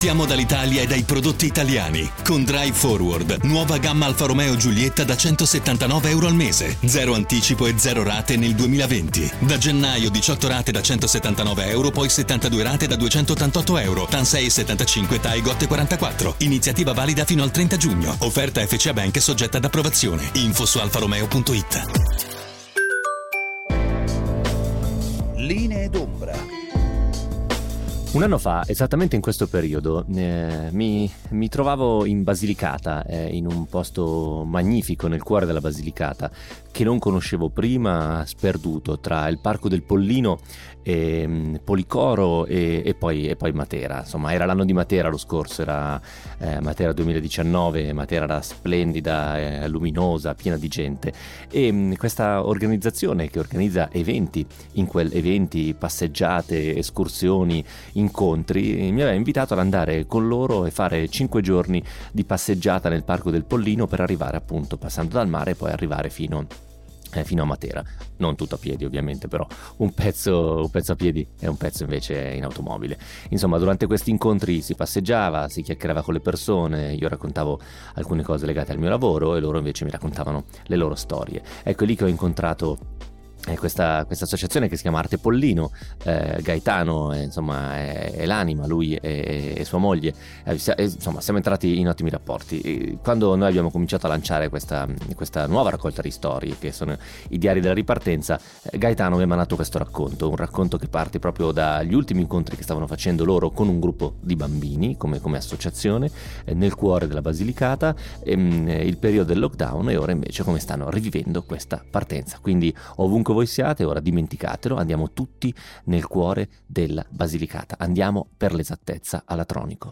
Podcast e interviste